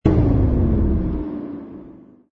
engine_oe_h_fighter_kill.wav